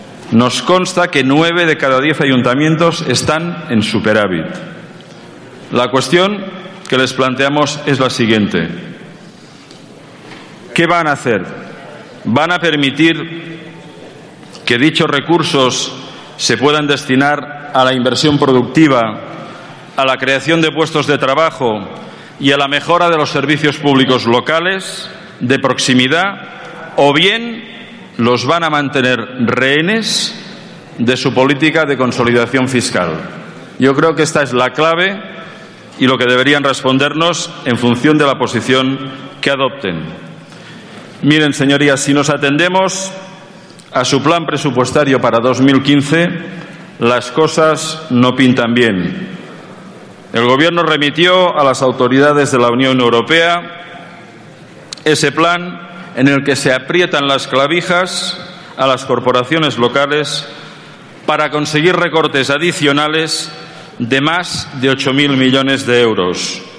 Fragmento de la intervención de Joan Rangel en el pleno del Congreso. Proposicion de Ley del Grupo Parlamentario Socialista, para el impulso a la inversión sostenible de las entidades locales.